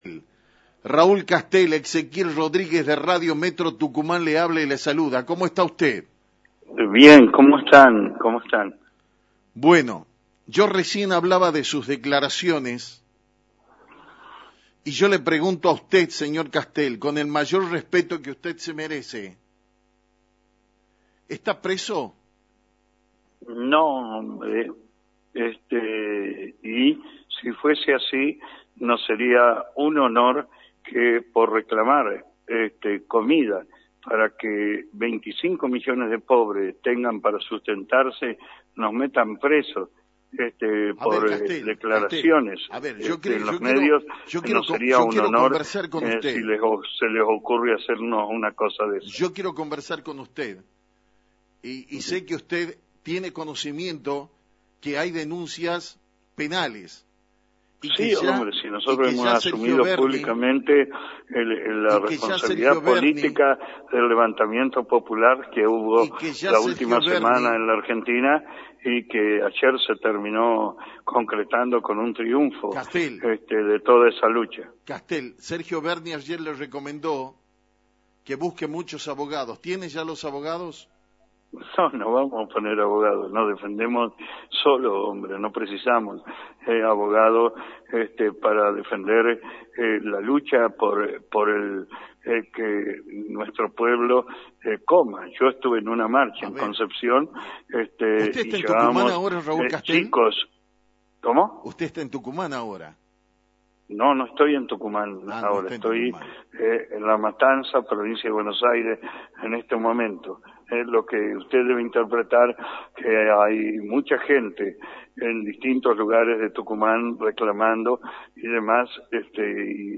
En diálogo exclusivo con Actualidad en Metro